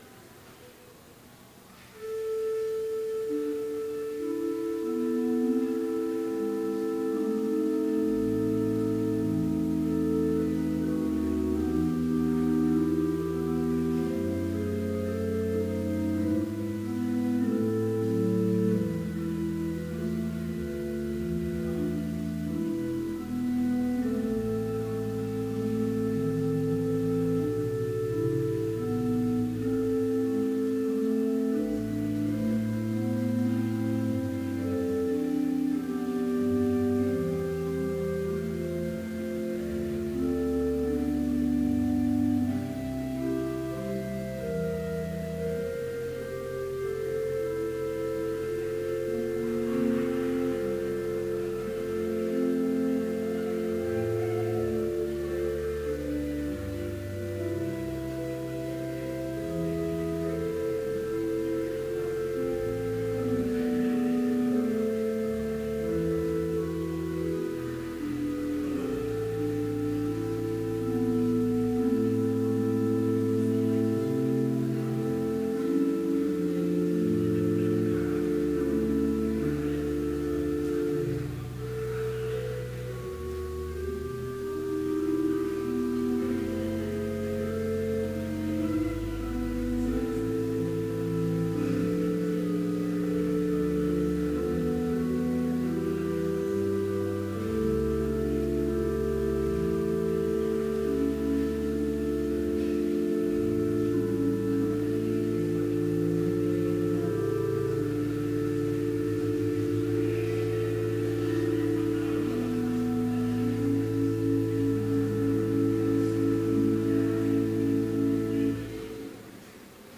Complete service audio for Chapel - February 7, 2017